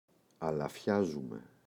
αλαφιάζουμαι [alaꞋfçazume]